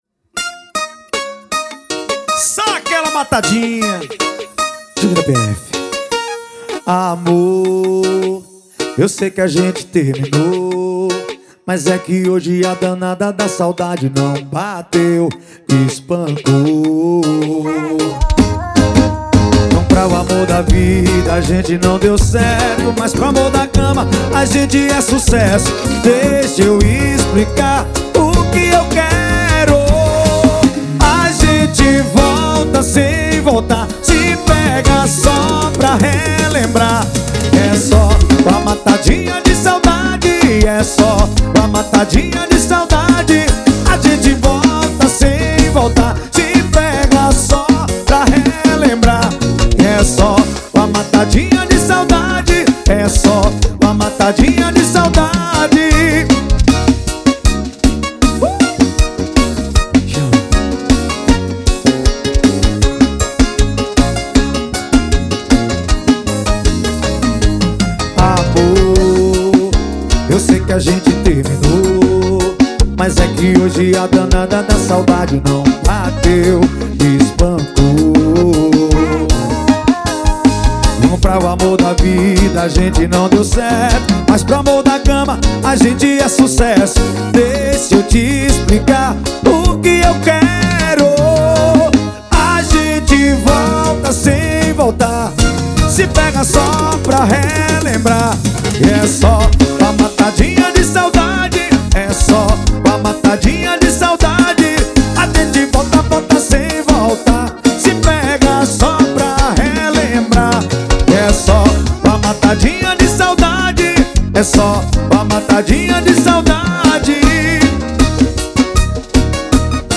2024-02-14 18:42:51 Gênero: FORRO Views